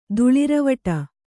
♪ duḷiravaṭa